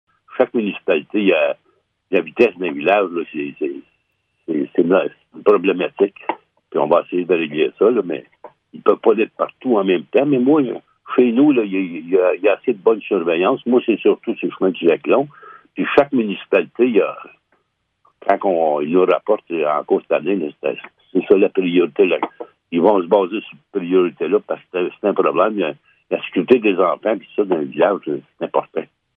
Les maires estiment que les excès de vitesse sont un problème qui persiste sur le territoire, autant sur les routes que sur les lacs. Voici ce que Laurent Fortin, le maire de Blue Sea, avait à dire concernant les problématiques de vitesse sur les routes :